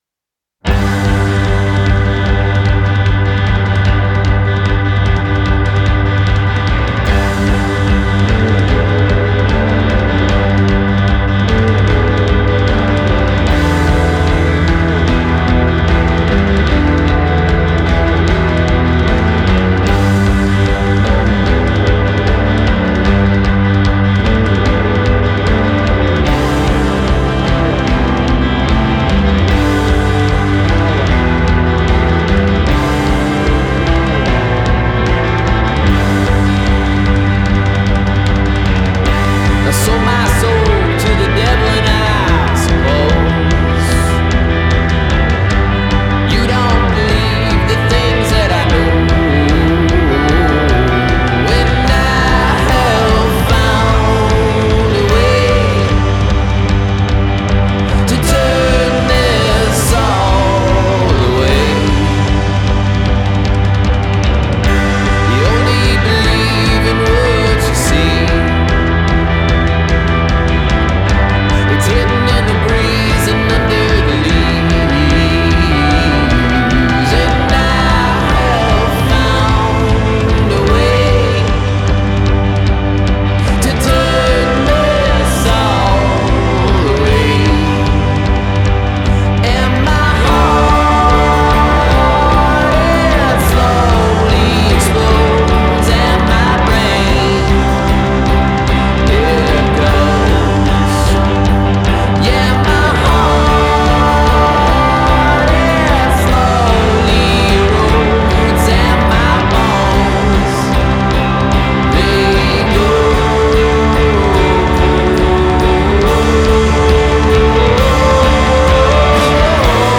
the result is ragged Americana paired with a punk spirit.